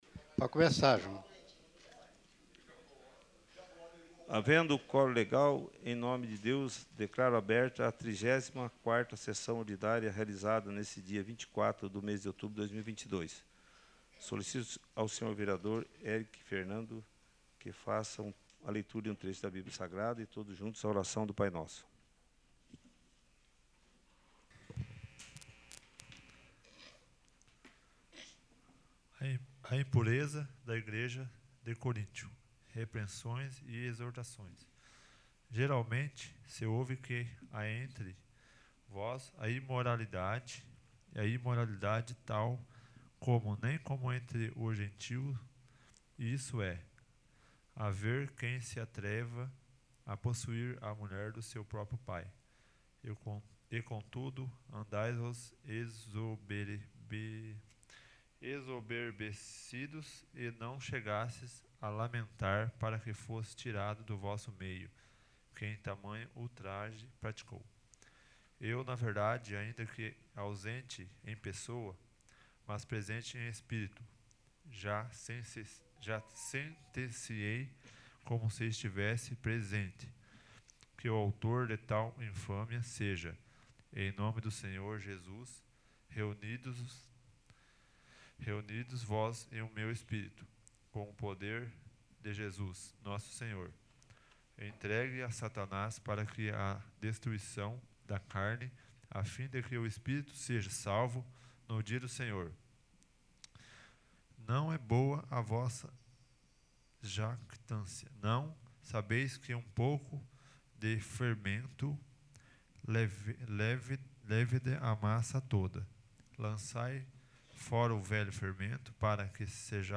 34º. Sessão Ordinária